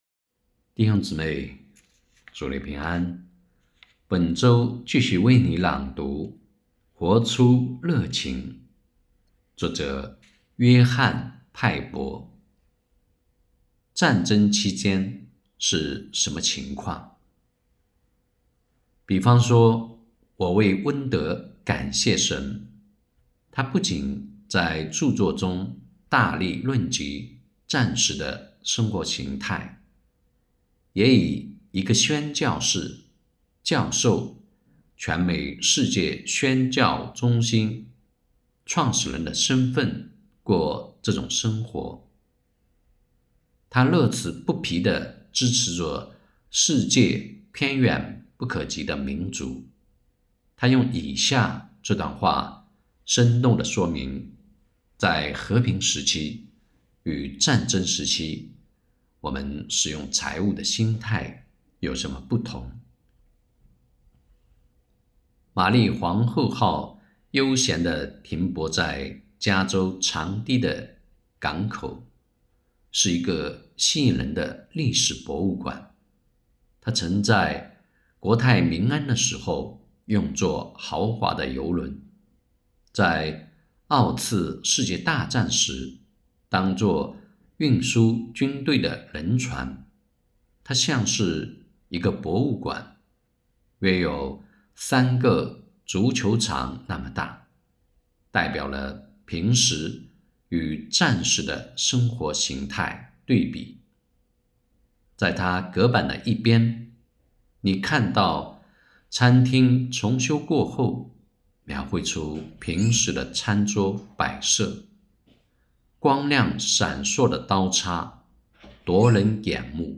2024年5月7日 “伴你读书”，正在为您朗读：《活出热情》 欢迎点击下方音频聆听朗读内容 https